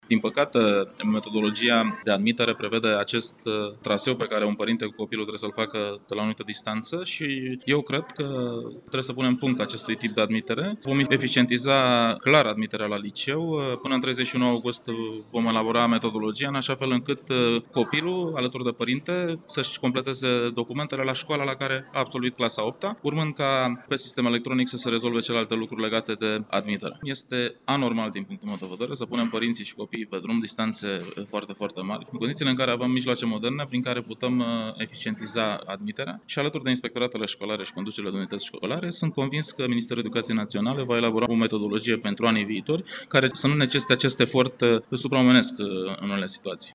Într-o declarație pentru Europa FM, ministrul Educației anunță că vrea simplificarea procedurilor pentru înscrierea absolvenților de gimnaziu la licee.